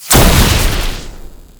poof.wav